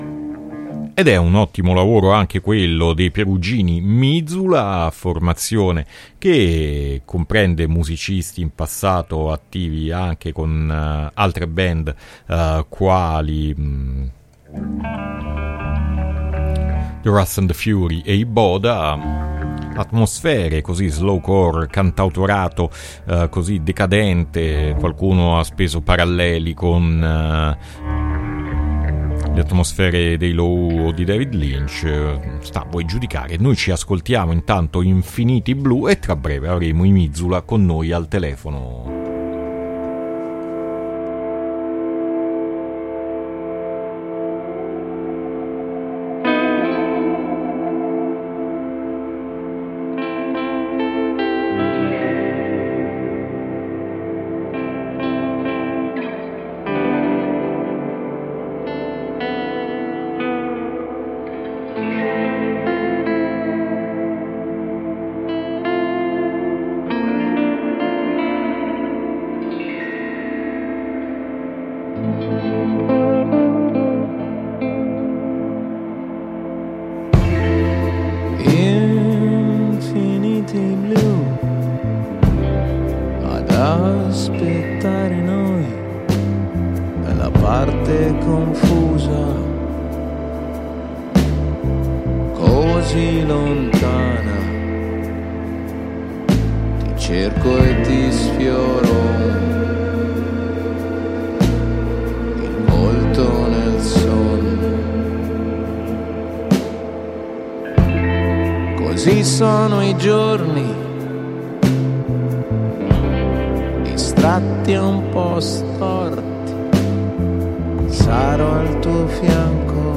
INTERVISTA MIZULA AD ALTERNITALIA 1-7-2022